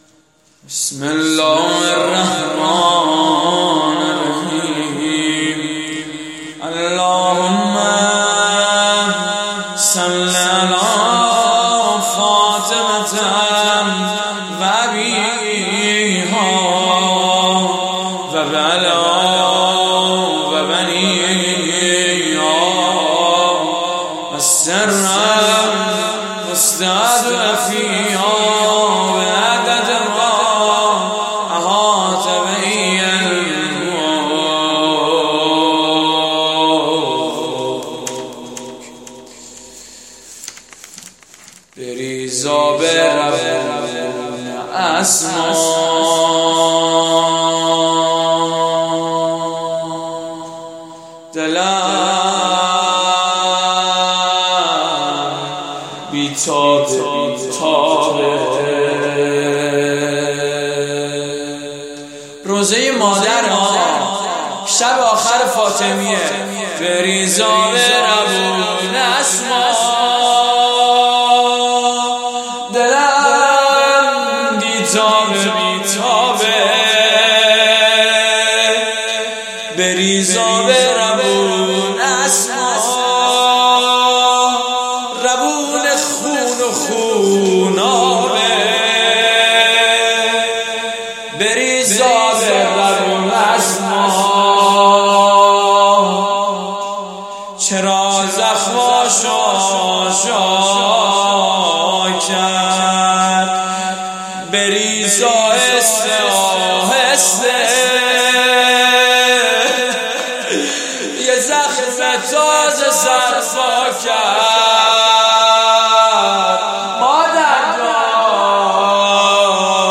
روضه شب اول فاطمیه اول مسجد شهید مصطفی خمینی(ره)